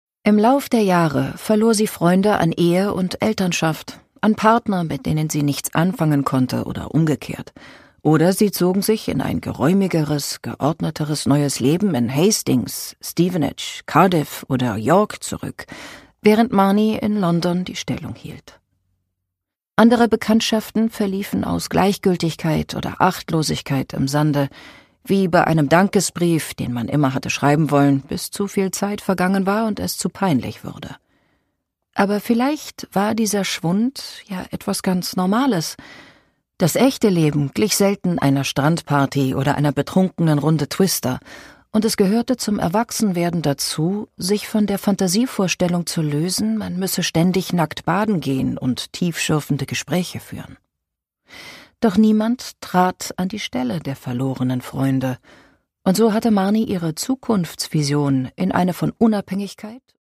David Nicholls: Zwei in einem Leben (Ungekürzte Lesung)
Produkttyp: Hörbuch-Download